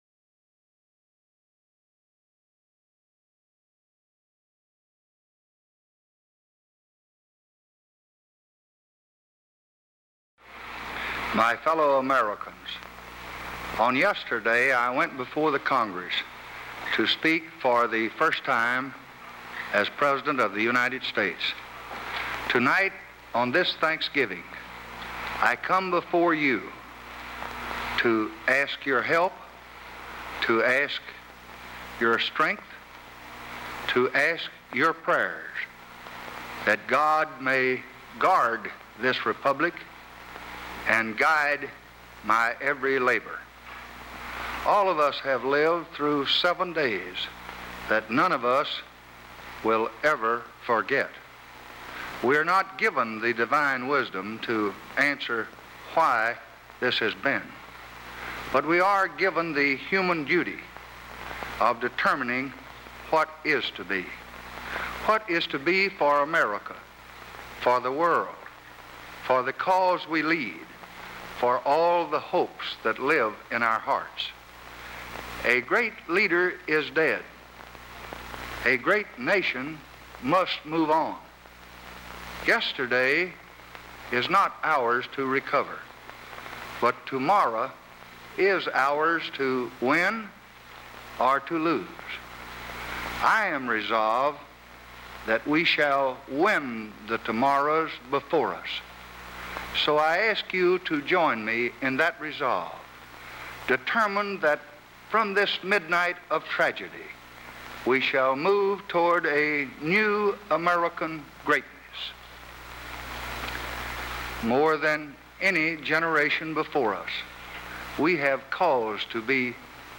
Presidential Speeches | Lyndon B. Johnson Presidency